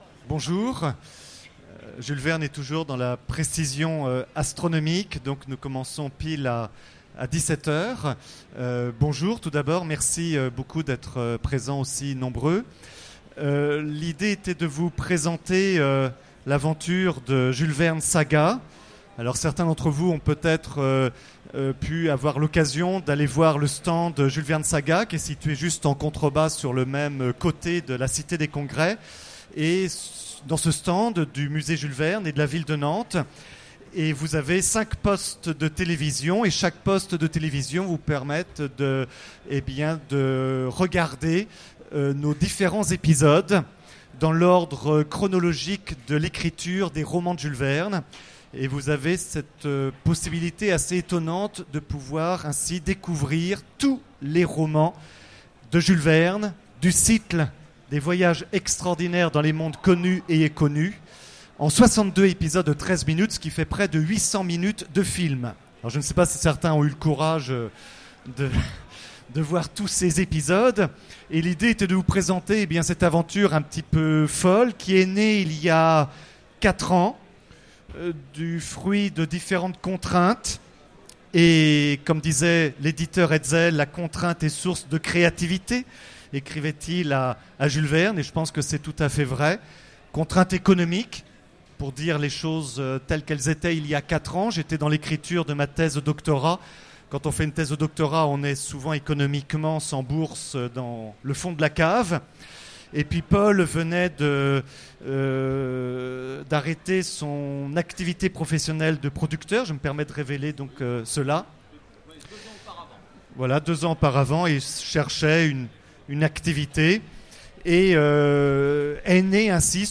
Utopiales 2016 : Conférence Jules Verne Saga